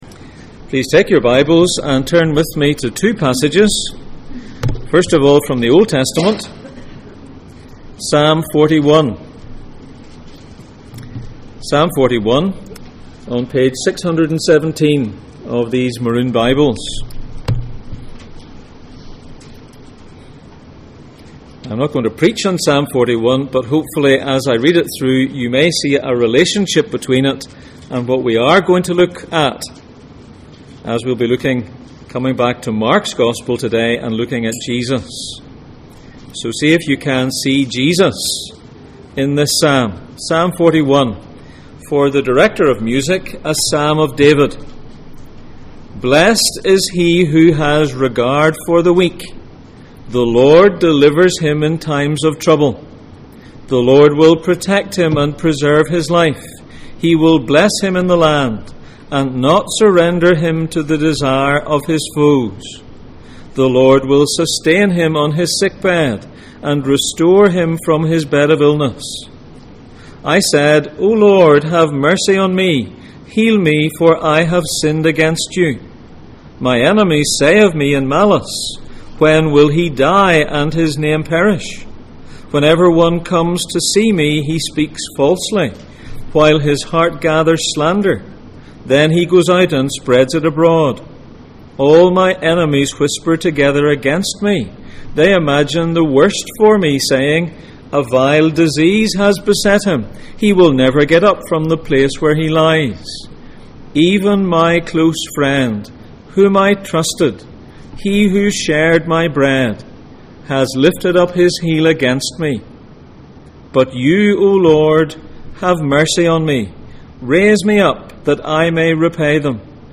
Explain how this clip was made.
Jesus in Mark Passage: Mark 14:1-11, Psalm 41:1-13, John 11:25-26 Service Type: Sunday Morning